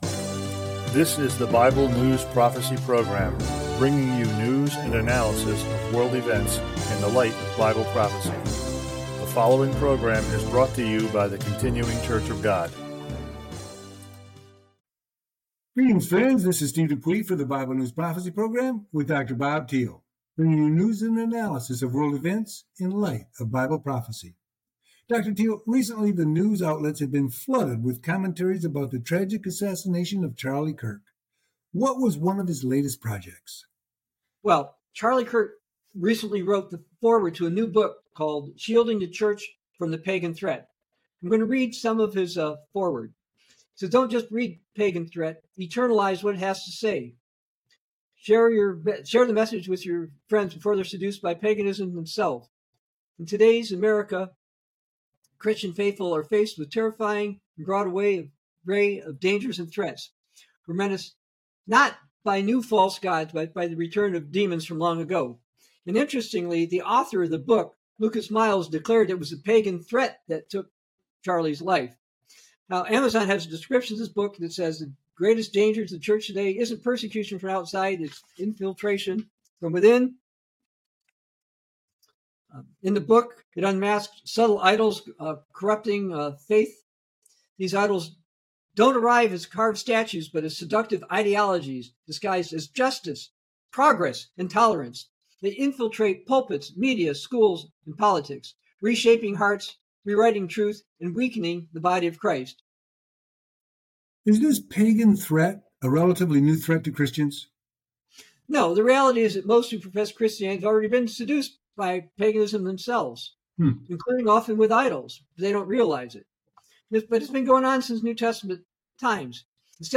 Bible News Prophecy Talk Show